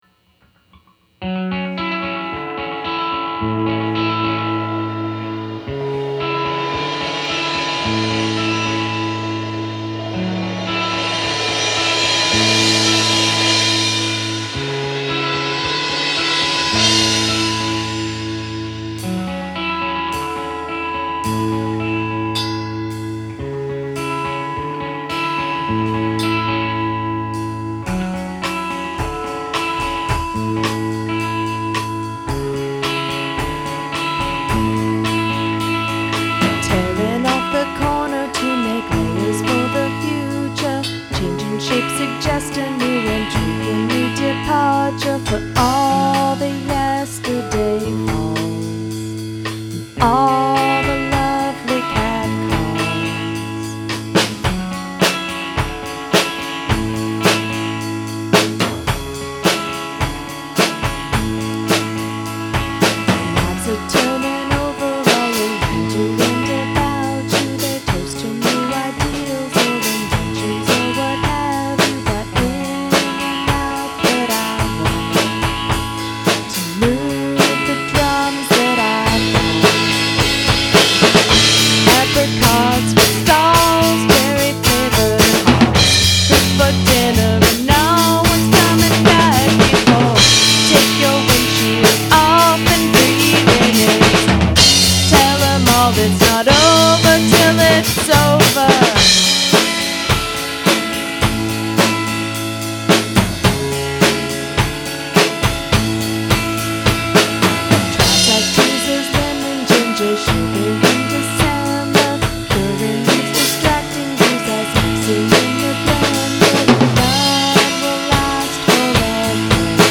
3-2-2010 Recording Session